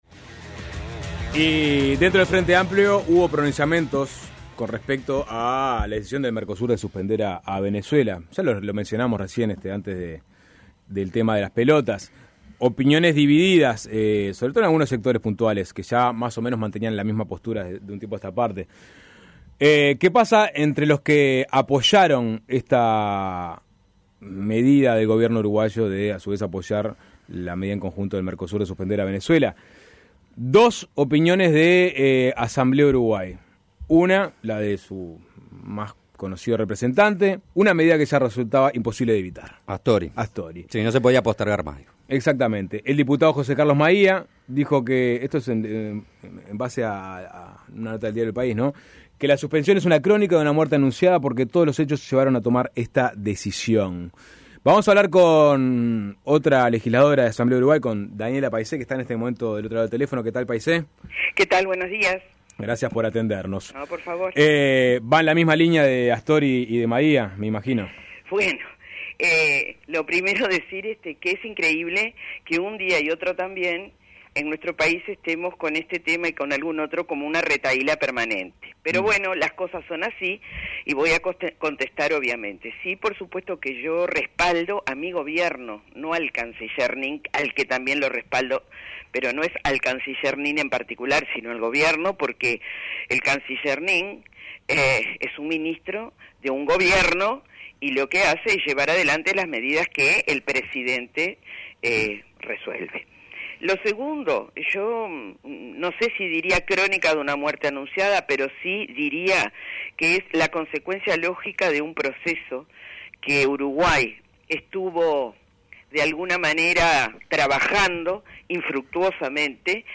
Entrevistada por Suena Tremendo